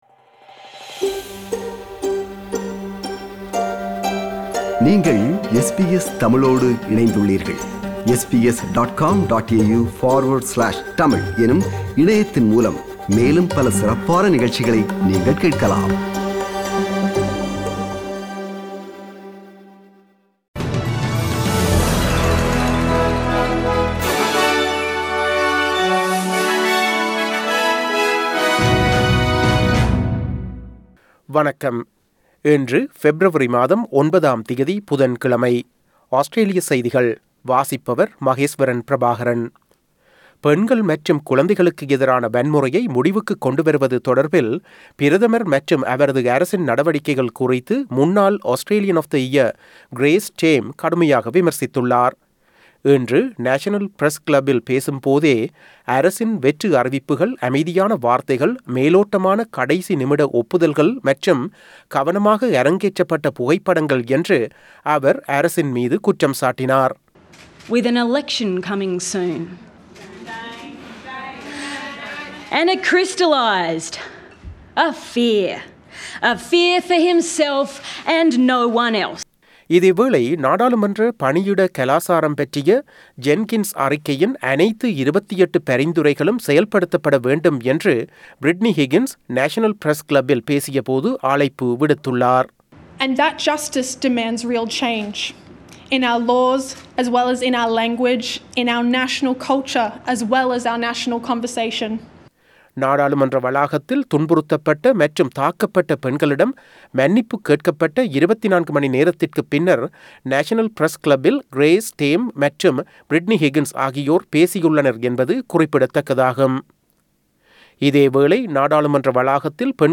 Australian news bulletin for Wednesday 09 February 2022.